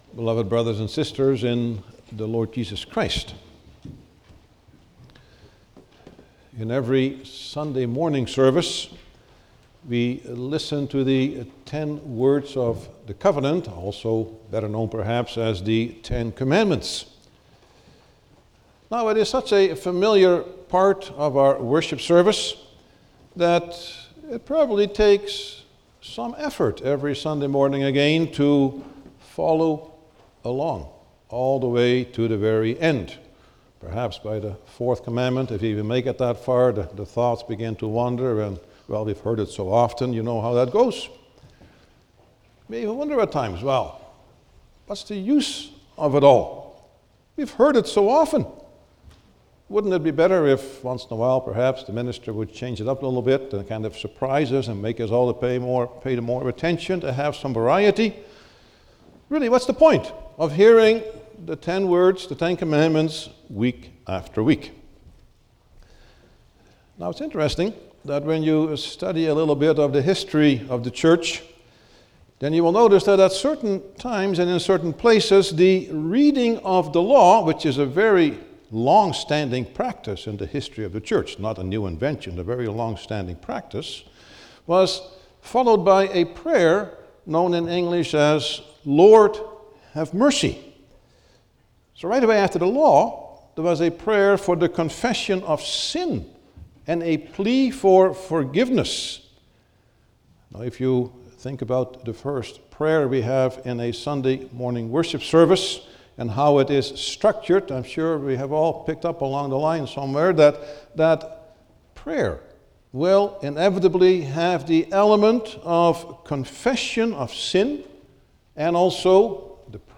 07-Sermon.mp3